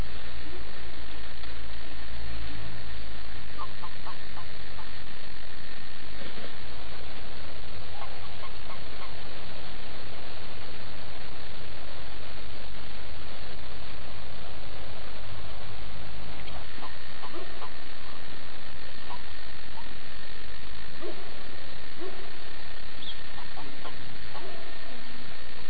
I tylko to gęganie jest jedynym sygnałem rozpoznawczym, który informuje nachalnego samczyka: "spadaj pacanie, ja też jestem chłop!". Poniżej umieściłem odgłosy z mojego stawu i mały filmik.
gęganie samców ropuchy
geganie_ropuch.mp3